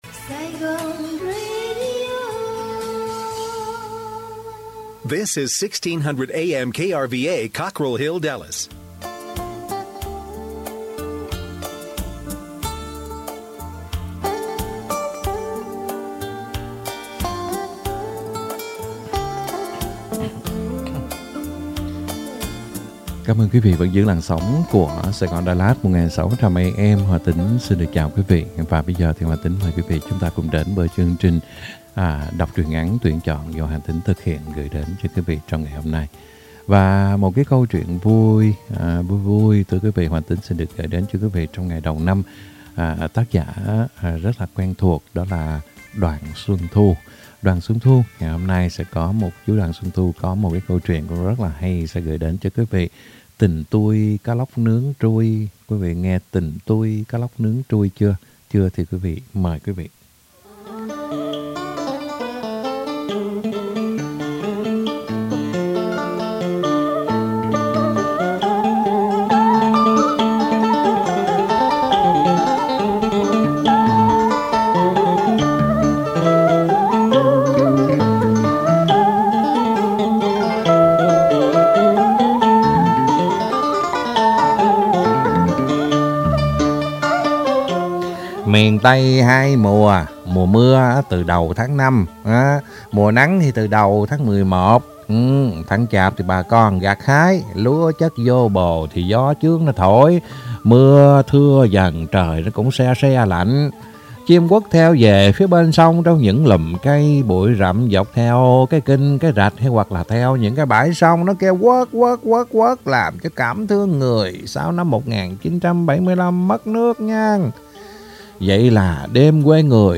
Đọc Truyện Ngắn = Tình Tui Cá Lóc Nướng Trui !